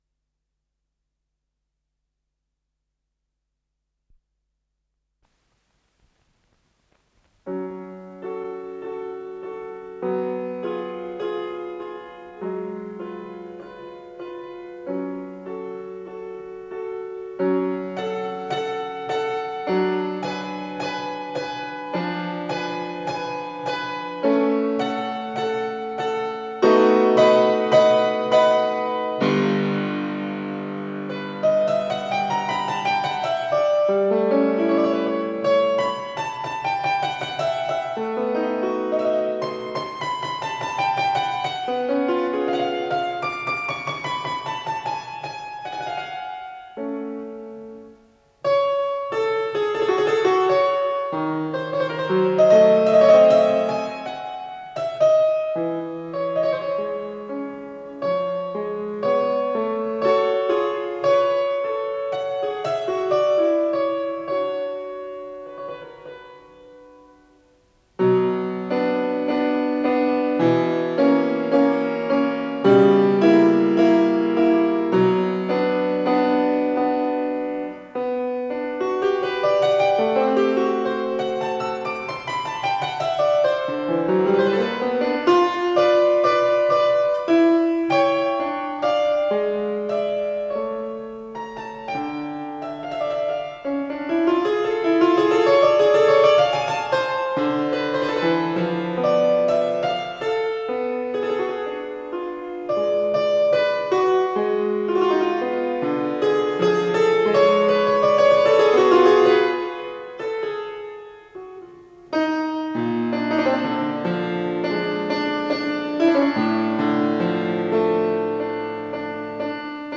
Исполнитель: Алексей Любимов - старинное молоточкое фортепиано
Фа диез минор